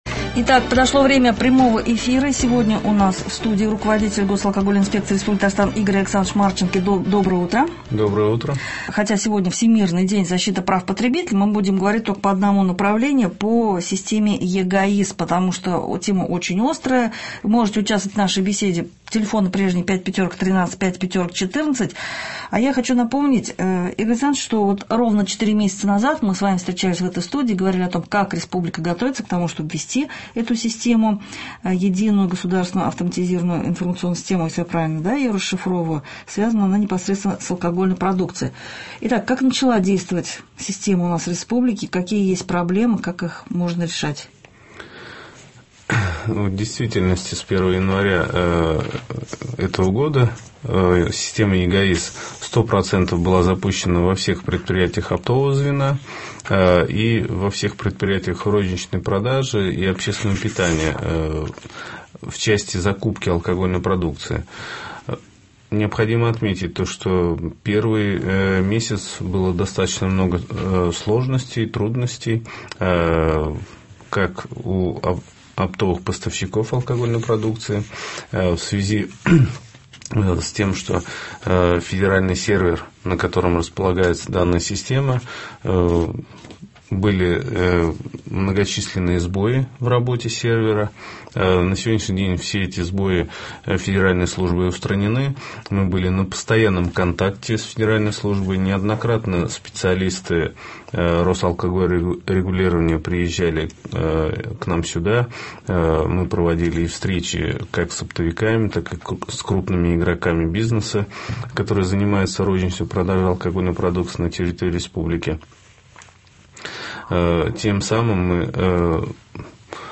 Прямой радиоэфир на ГТРК
Видео 1 из 1 Прямой радиоэфир на ГТРК "Татарстан" с участием руководителя Госалкогольинспекции РТ Игоря Александровича Марченко Канал ГТРК "Татарстан" 15 марта 2016 г., вторник Вопросы по Единой Государственной автоматизированной информационной системы (ЕГАИС) Поделиться: Скачать видео высокого качества